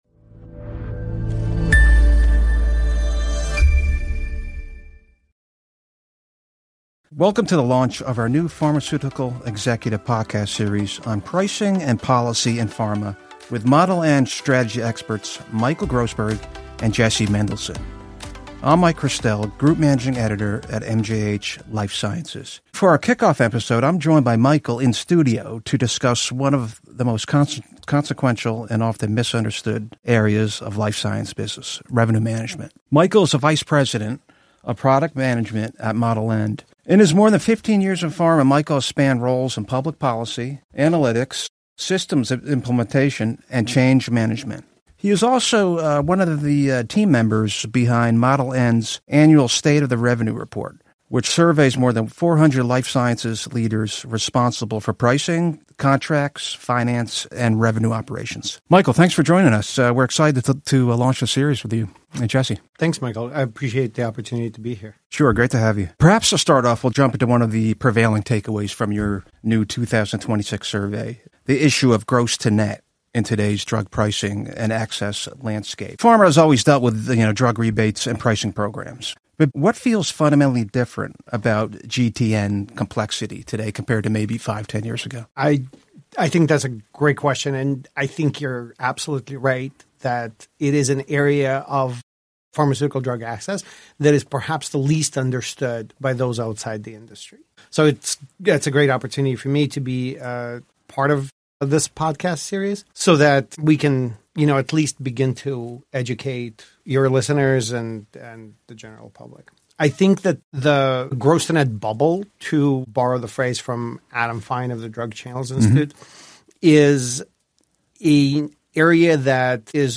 From PBM opacity and Medicaid withdrawal to "ghost claims" lurking inside DTC channels, this conversation pulls back the curtain on the industry's most consequential — and perhaps least understood — business challenge: revenue management.